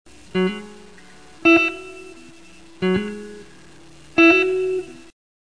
The first hammer-on is played on the 4th string from the 4th fret to the 6th fret.
The second hammer-on is played on the 2nd string from the 5th fret to the 7th fret.
The two hammer-on's notated above sound like this:
hammer.wav